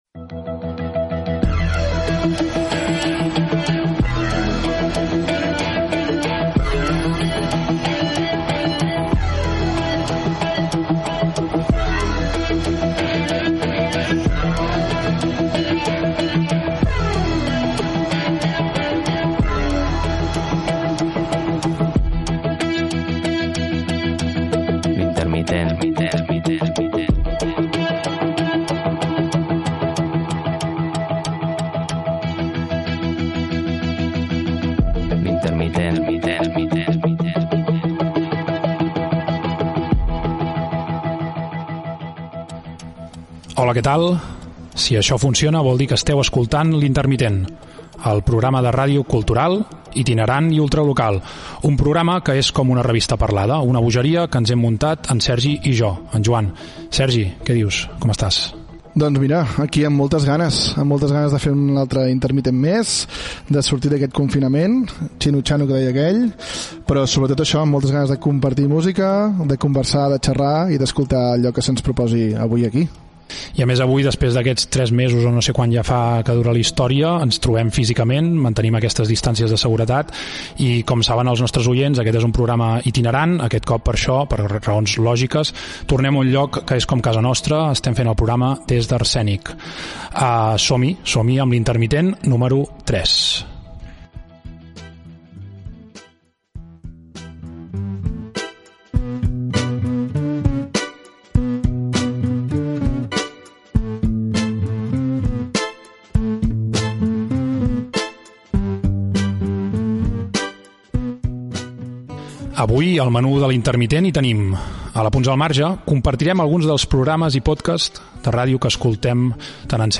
Tercer espai fet des del centre cultural d'Arsènic de Granollers.
Cultural